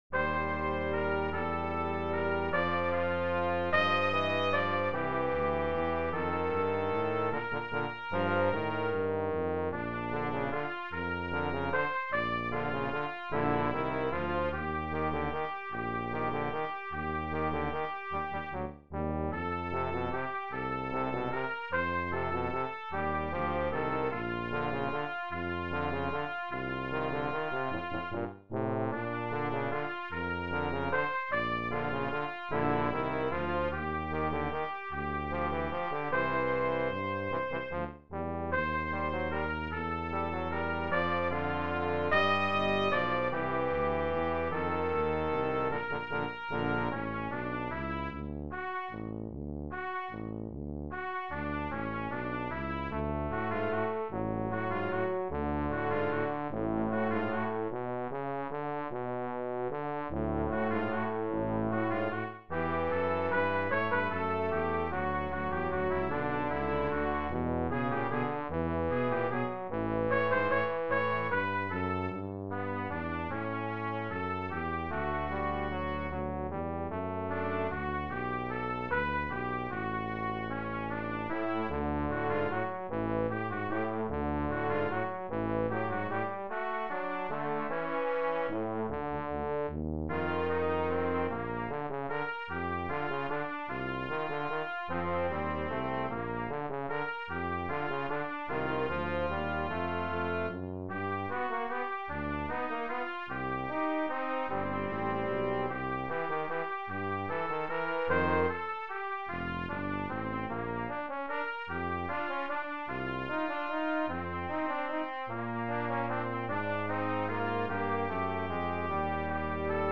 Brass Trio TTT
Traditional American songs
A medley of three classic waltzes from the early 1900's.